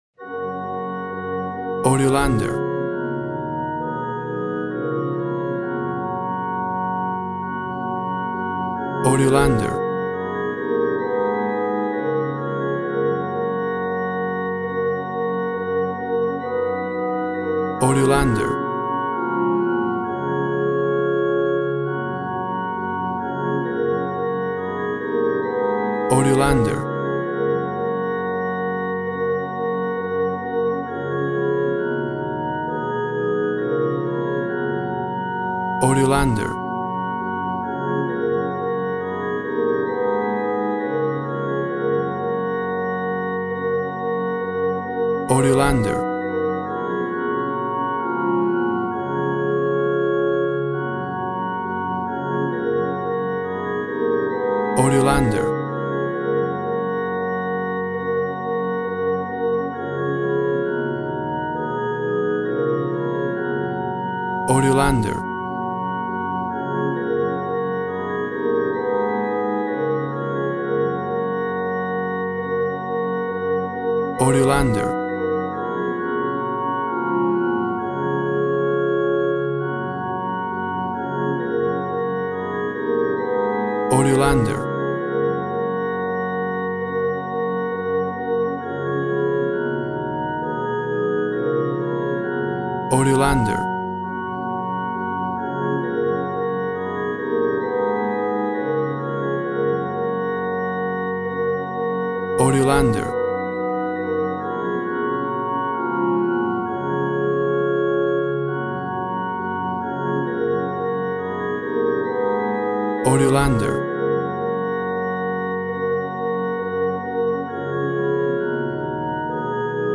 Old Christian hymn.
Tempo (BPM) 115